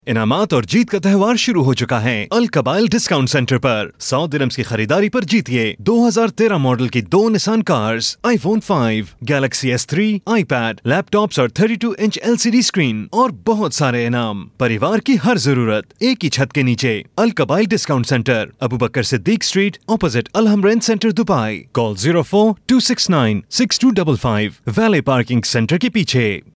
Urduca Seslendirme
Erkek Ses